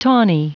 Prononciation du mot tawny en anglais (fichier audio)
Prononciation du mot : tawny